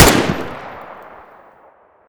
aug_shoot.ogg